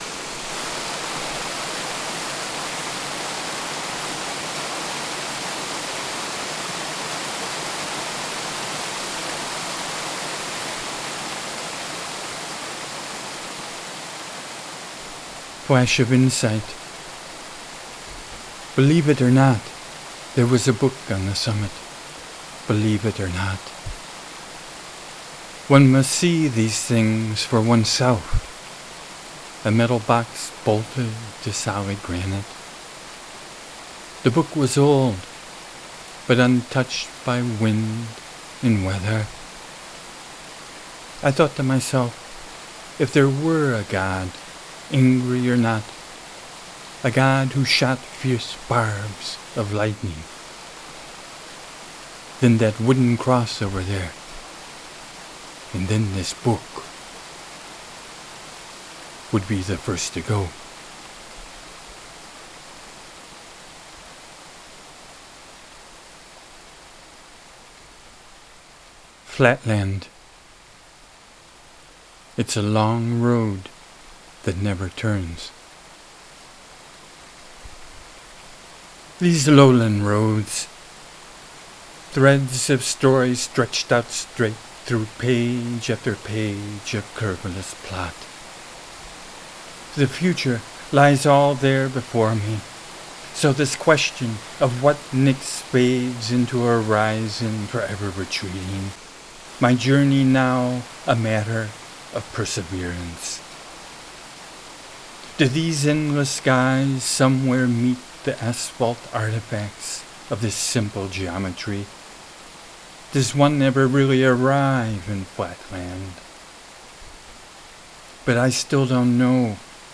DIPPER FALLS, polyphonic flowforms, (recording playing in back IS Dipper Falls!)
[the water in the background really IS DIPPER FALLS! |